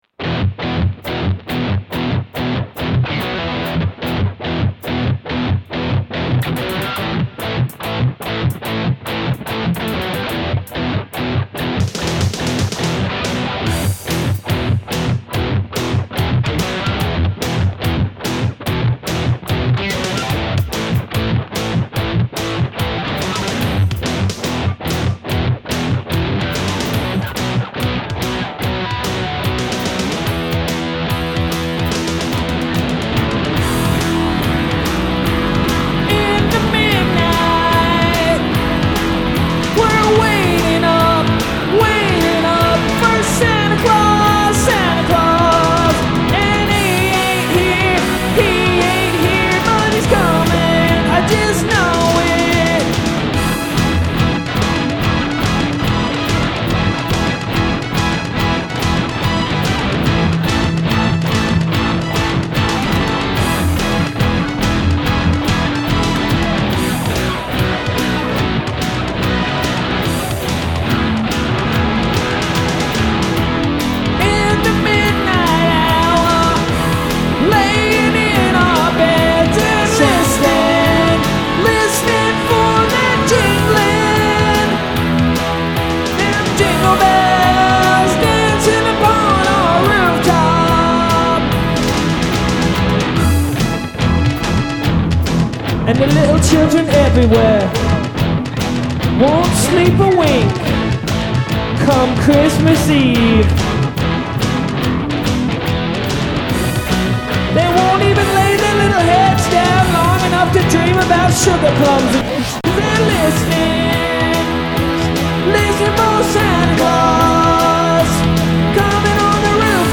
JingleJangleJingle_bleeped.mp3